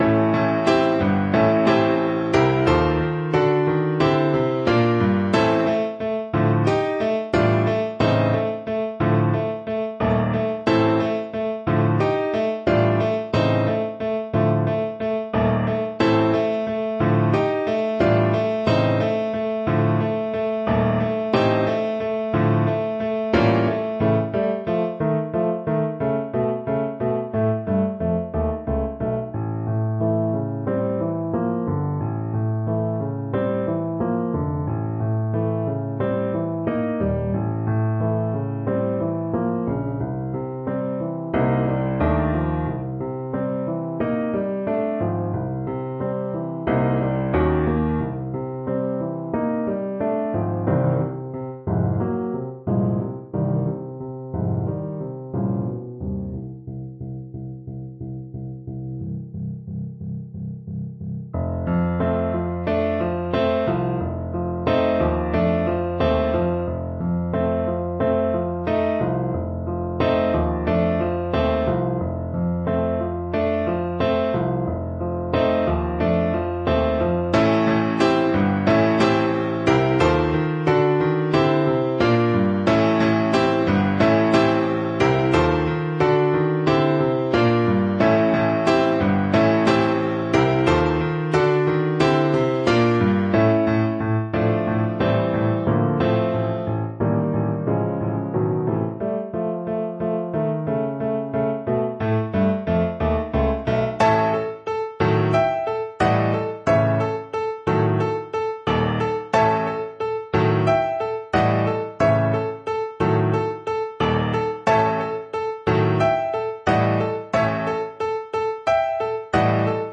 4/4 (View more 4/4 Music)
F3-C5
Fast and Forceful = c. 140